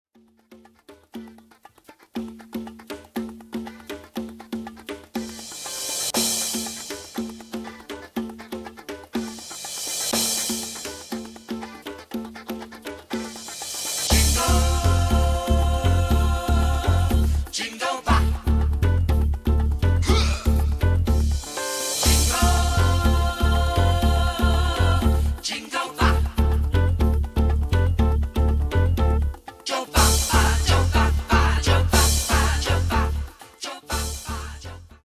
Genre:   Latin Disco